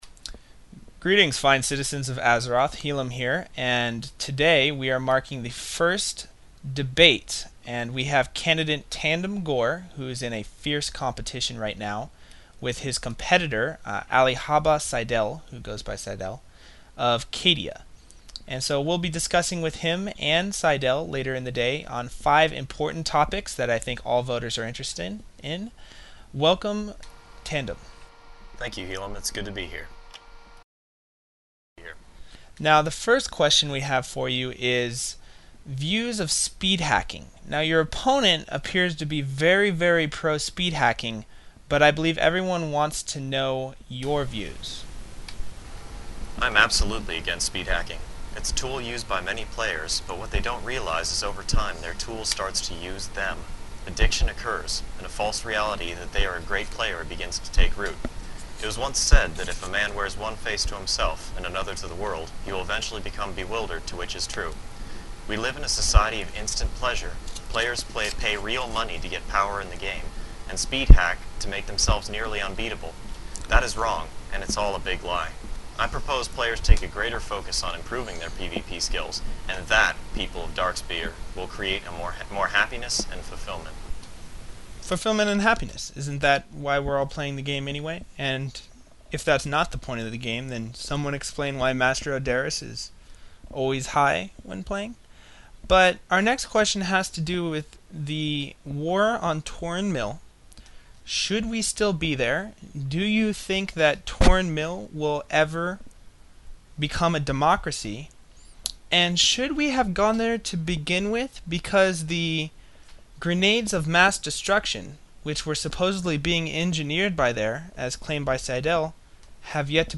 Greetings Fine Citizens of Azeroth, As many of you know the Merciless Midgets have been in a HOT election race for the Reward Tabard which will be given out by the Game Masters on the 24th. As a result we have scheduled this Debate tonight between both candidates.